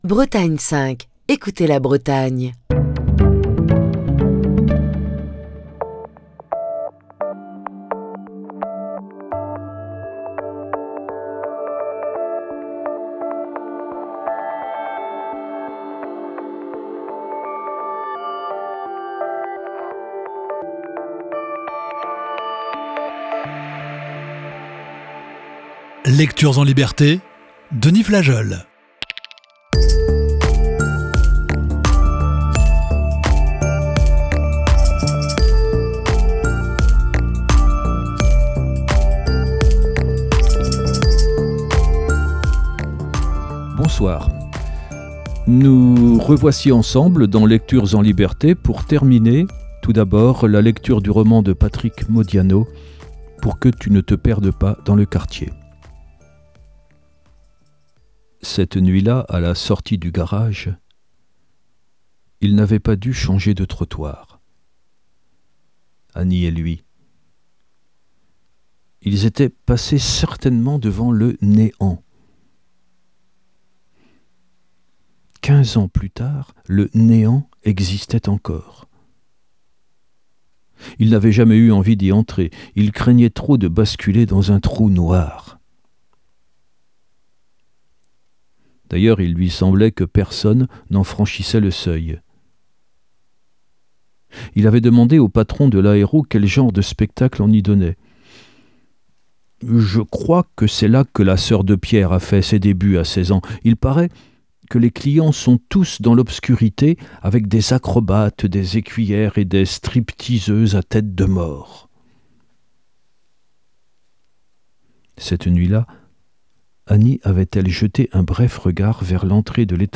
lit la dernière partie du récit et un texte et d'Arthur Rimbaud, "Lettres du voyant".